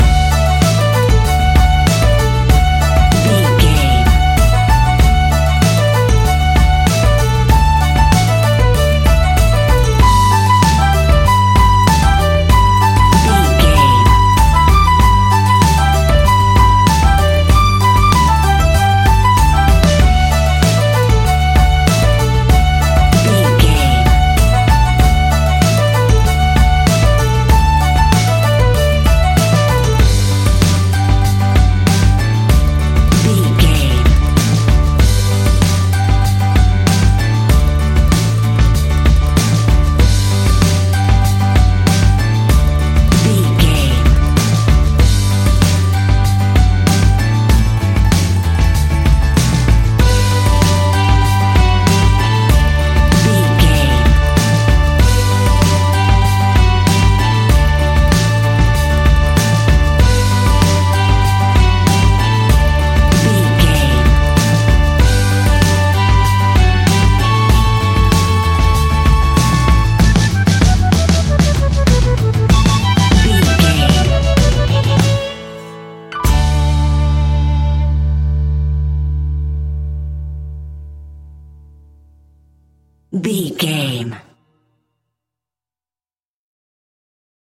Uplifting
Ionian/Major
D
acoustic guitar
mandolin
ukulele
lapsteel
drums
double bass
accordion